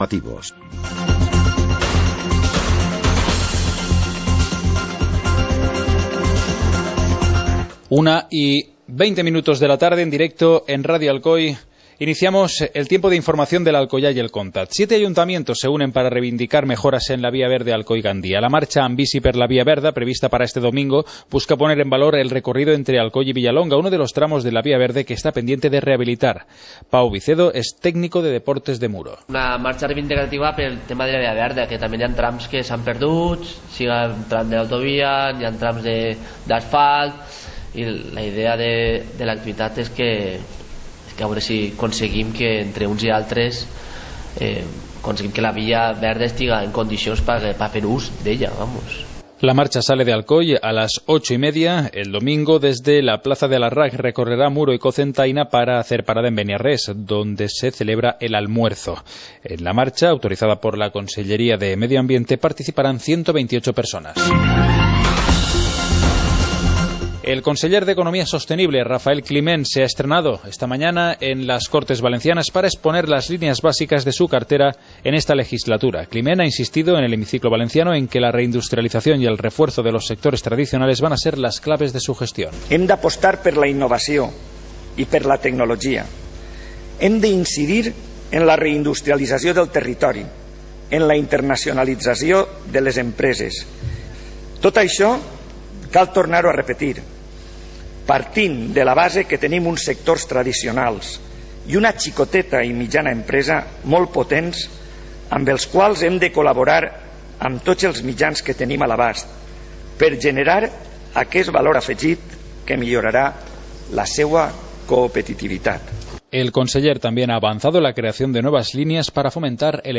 Informativo comarcal - miércoles, 23 de septiembre de 2015